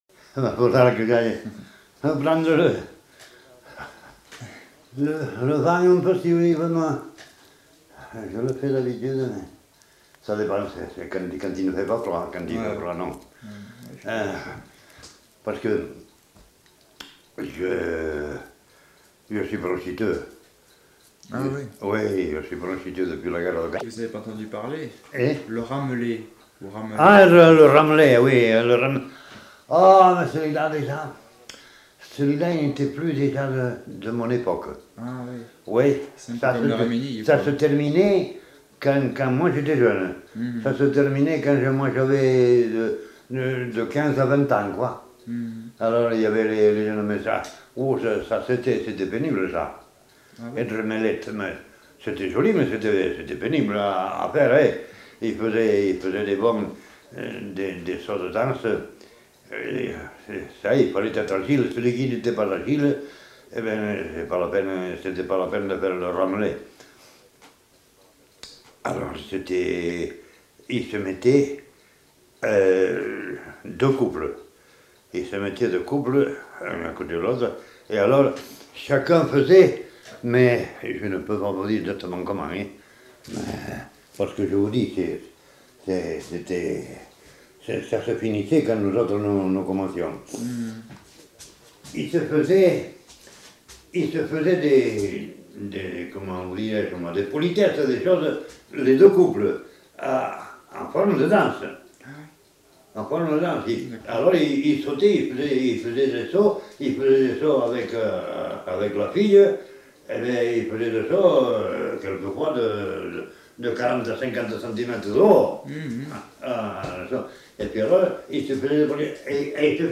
Aire culturelle : Couserans
Lieu : Uchentein
Genre : témoignage thématique